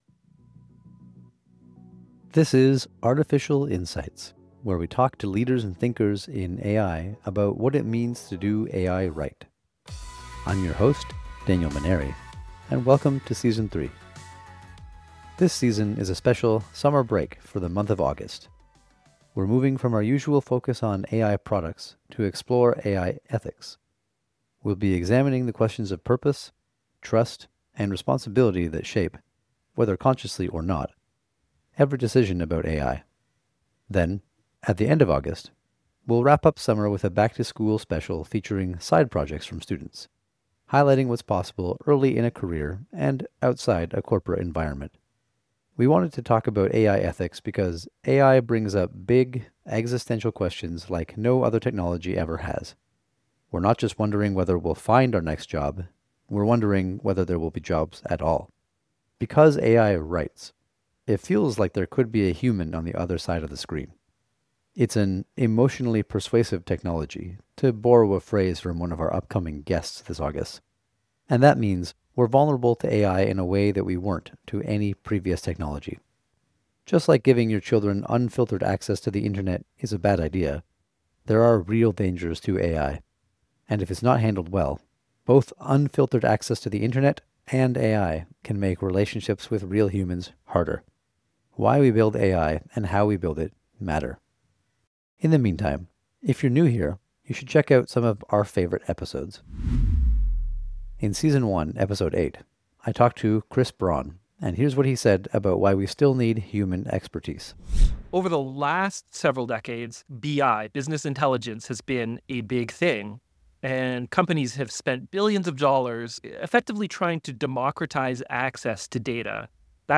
Play Rate Listened List Bookmark Get this podcast via API From The Podcast 1 Candid conversations and real-world stories about building AI into products and businesses.